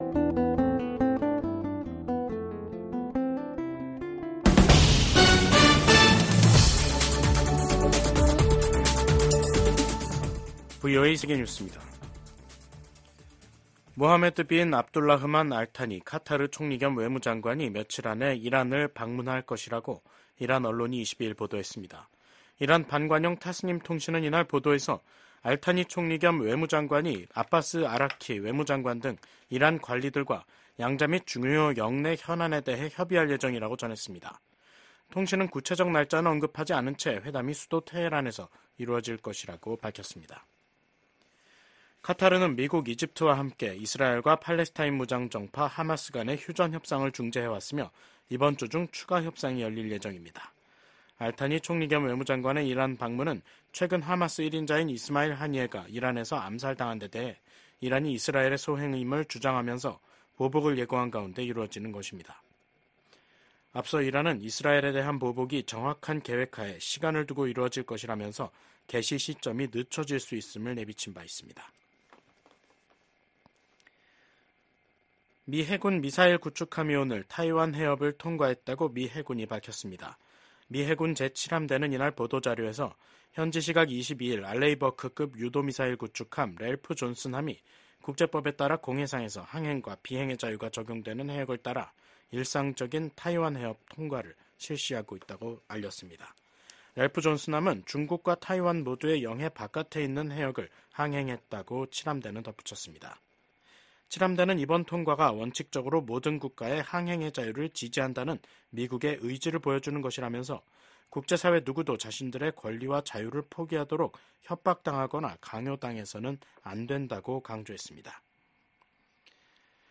VOA 한국어 간판 뉴스 프로그램 '뉴스 투데이', 2024년 8월 22일 3부 방송입니다. 미국 국방부는 미한 연합훈련인 을지프리덤실드 연습이 방어적 성격이란 점을 분명히 하며 ‘침략 전쟁 연습’이란 북한의 주장을 일축했습니다. 주한 미 공군 전투기들이 23일까지 준비태세훈련을 실시합니다. 미국 정부가 윤석열 한국 대통령의 대북 접근 방식을 지지한다고 밝혔습니다.